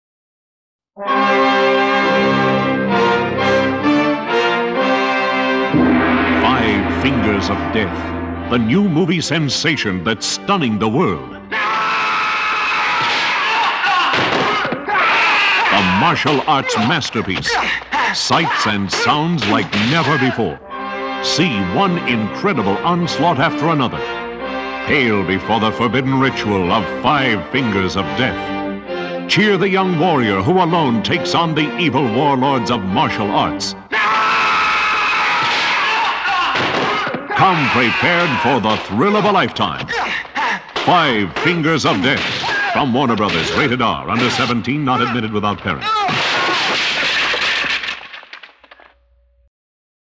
Radio Spots
So now, though, put on your gi or your Kung Fu uniform, assume your beginning stance, and listen to radio spots for the movie that started it all.